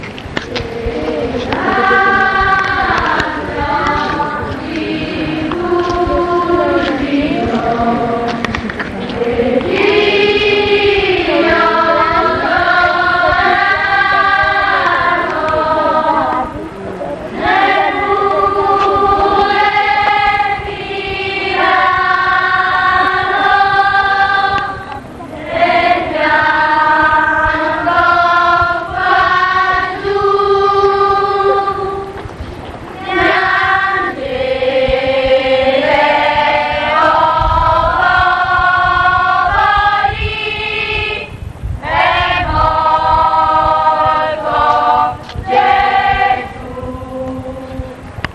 Ovviamente le registrazioni sono a livello amatoriale, per lo più registrate "live" sulle strade, se qualcuno fosse in possesso di registrazioni migliori può, se lo desidera, inviarcele in modo che esse siano messe a disposizione di tutti.